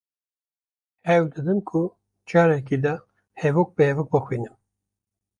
I try to read one sentence at a time. Read more sentence (grammatically complete series of words consisting of a subject and predicate) Frequency B2 Pronounced as (IPA) /hɛˈvoːk/ Etymology From hev + -ok.